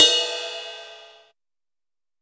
RIDE 900.WAV